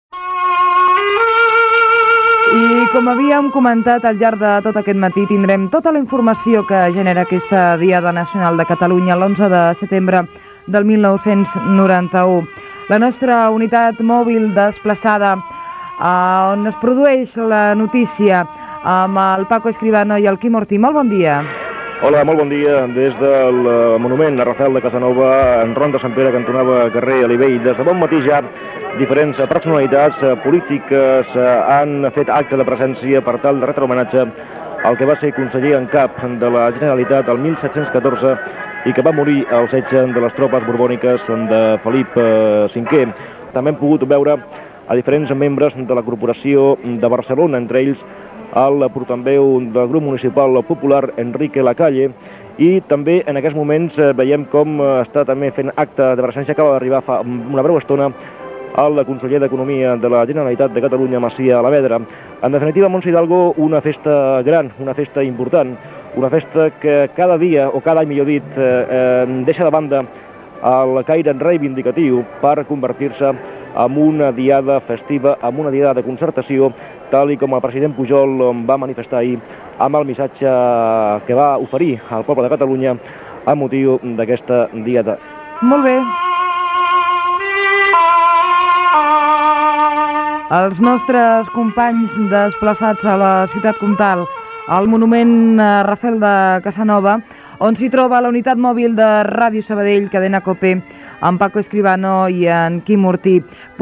Connexió amb el monument a Rafel Casanova, a Barcelona, per informar dels representants polítics que han fet una ofrena floral a qui va ser el darrer conseller en cap de Barcelona en la Diada Nacional de Catalunya
Informatiu